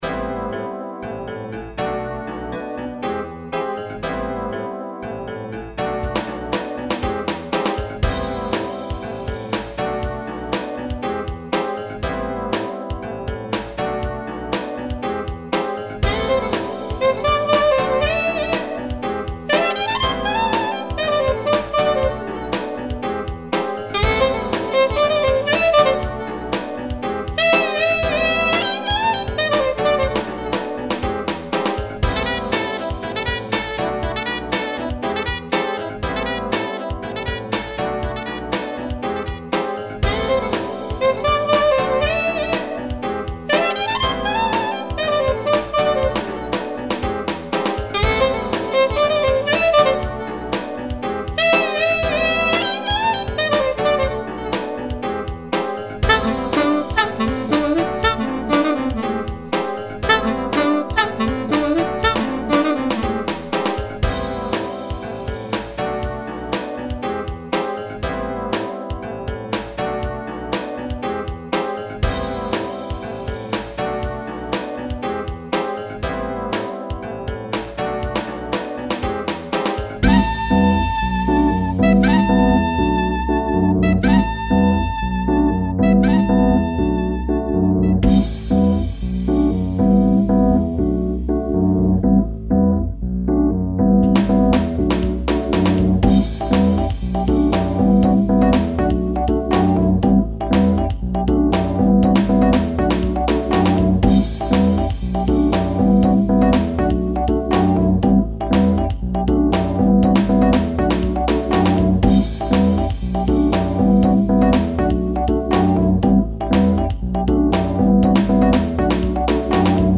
単調でもそれとなくおしゃれっぽく。